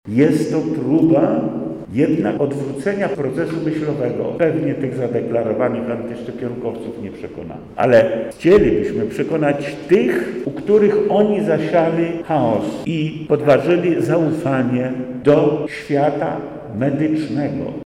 Pokładamy nadzieje w tej akcji – dodaje wojewoda lubelski Lech Sprawka: